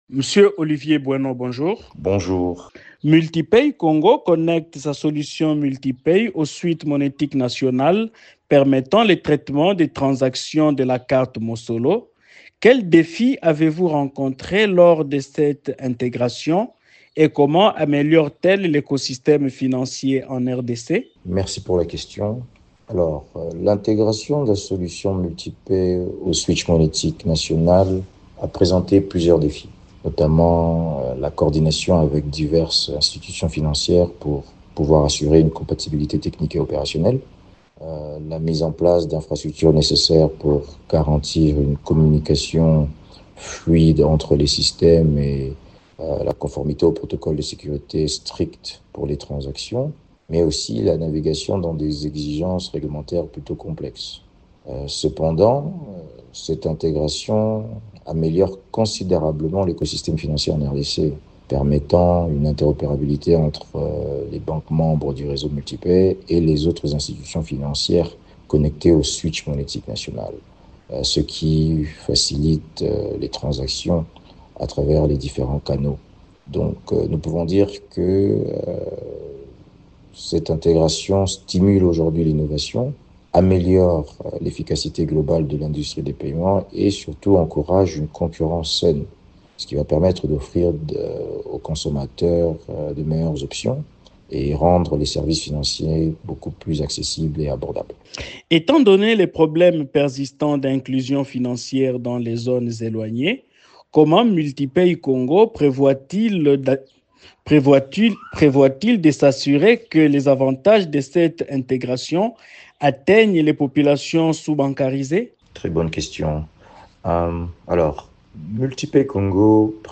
Invité de Radio Okapi